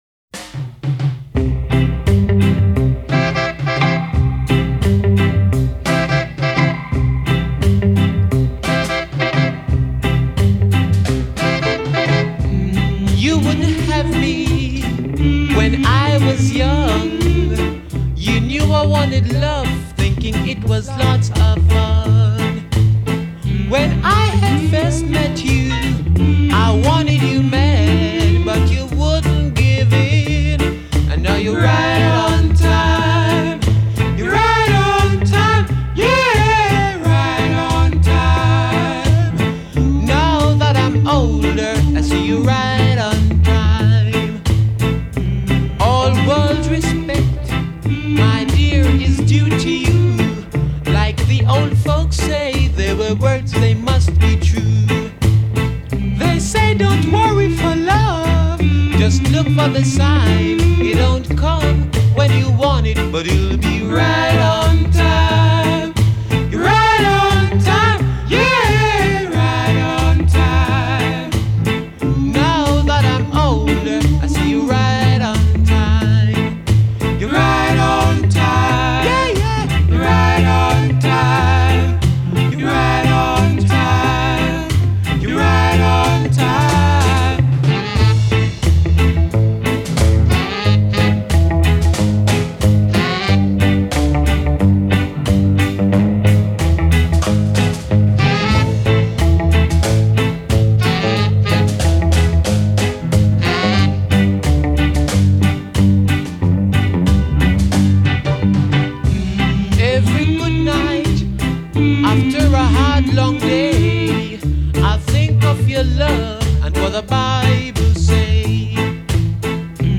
Out of the Rhythm & Blues and into the Rocksteady
majestic cover